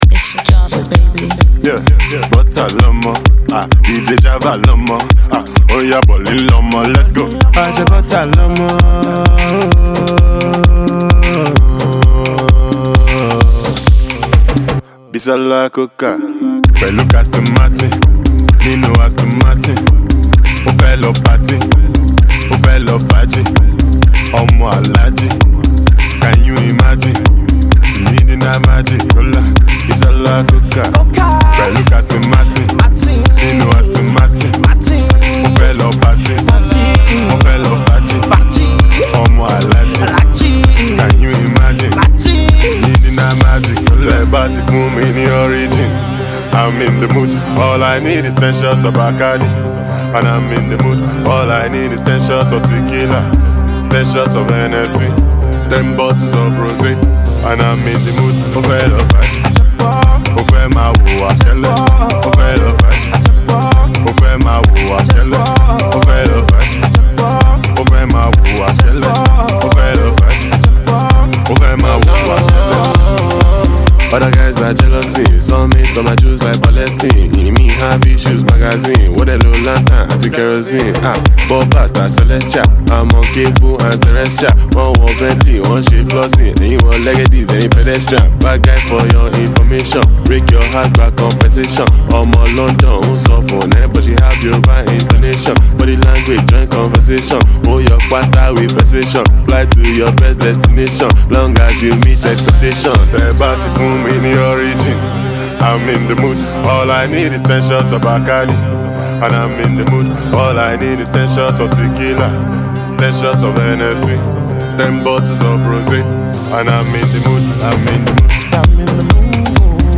alternative rapper
house music